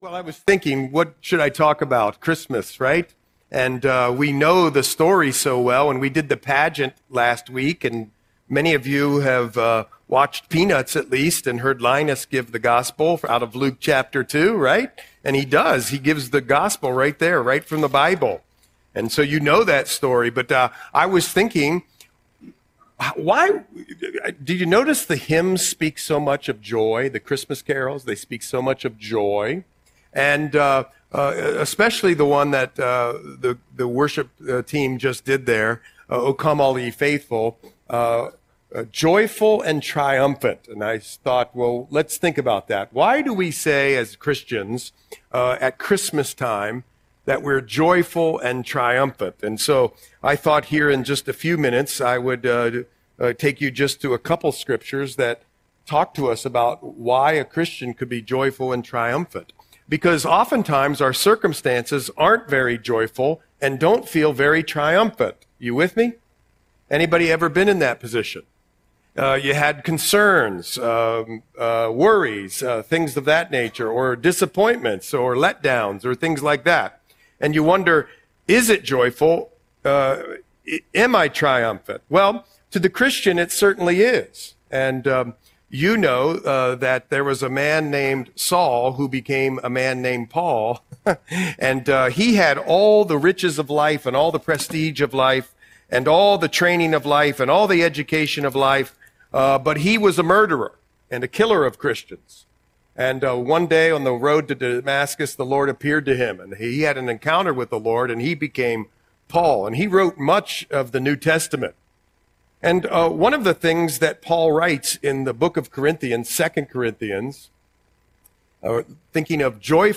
Audio Sermon - December 24, 2025